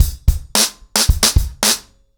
BlackMail-110BPM.25.wav